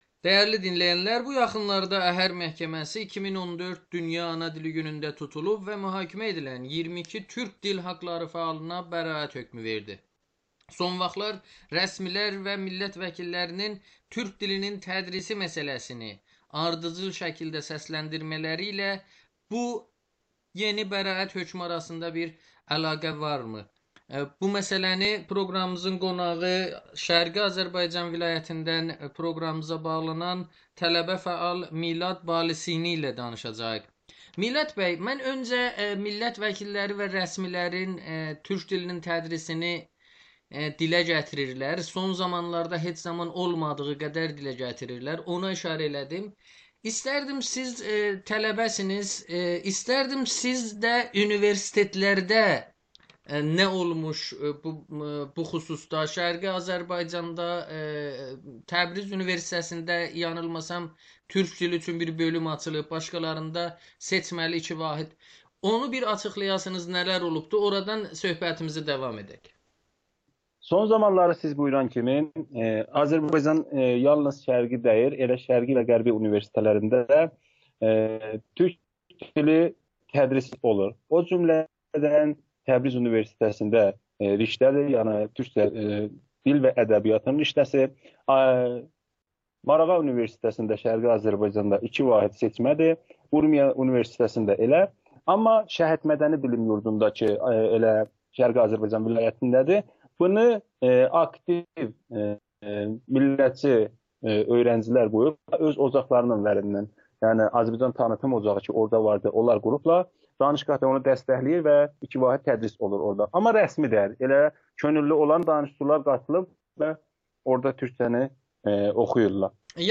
Güneyli tələbə fəal Amerikanın Səsinə danışır